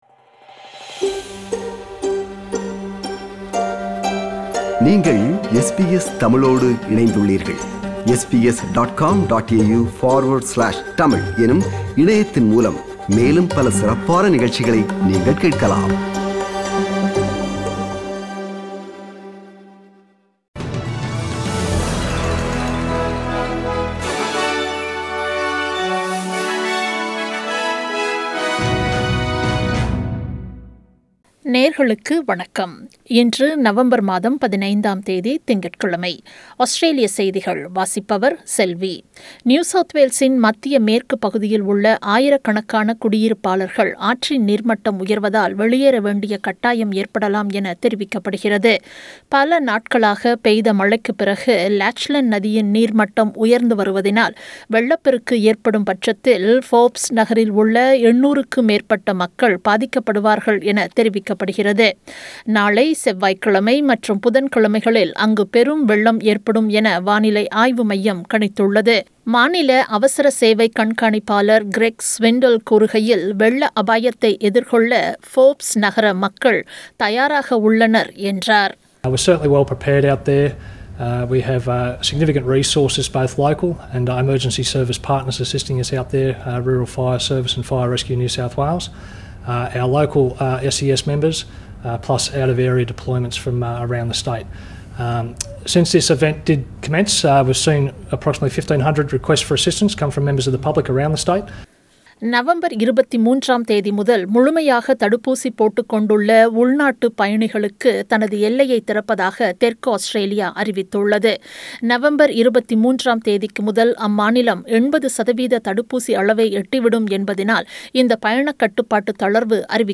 Australian News: 15 November 2021 – Monday